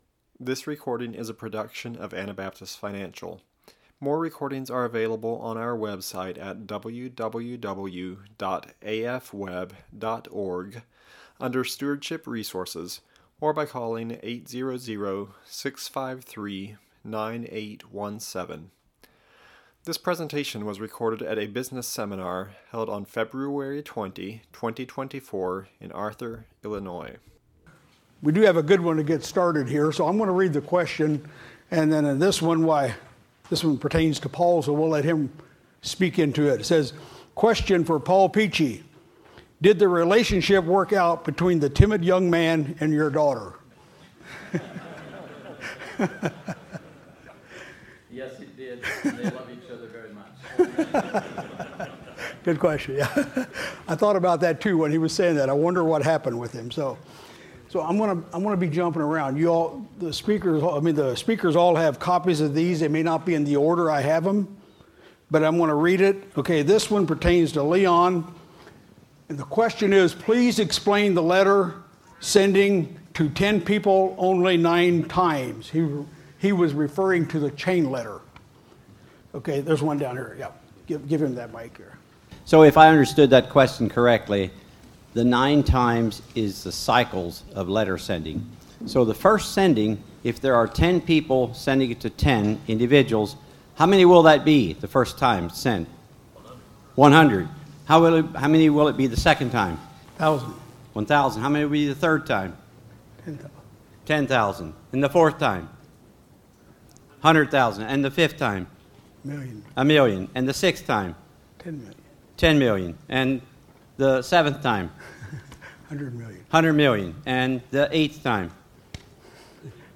Q&A Session with Speakers - Anabaptist Financial
An opportunity for the crowd to ask questions, seek clarification, or expand the topics presented.
question-and-answer-session-with-speakers-2024-arthur-il-seminar.mp3